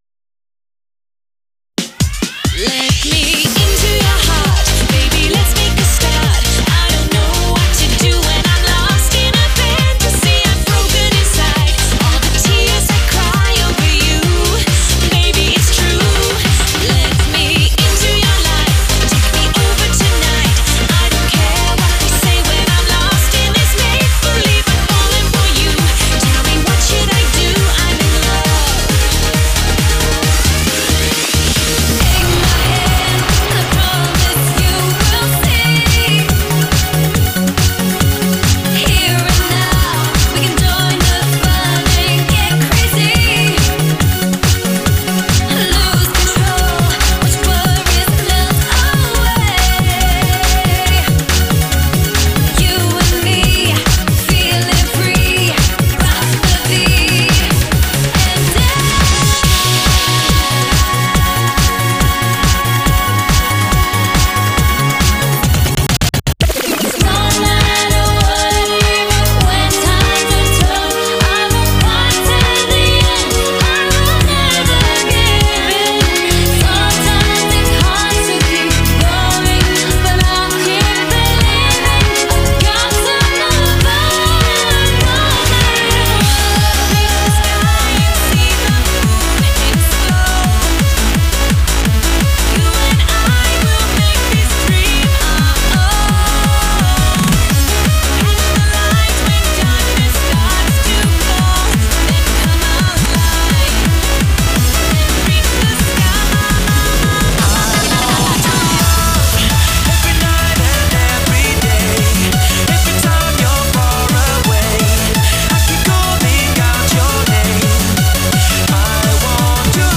BPM100-192
Audio QualityPerfect (High Quality)
ComentariosThe order of charts for this megamix are